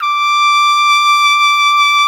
Index of /90_sSampleCDs/Roland L-CD702/VOL-2/BRS_Piccolo Tpt/BRS_Picc.Tp 2 St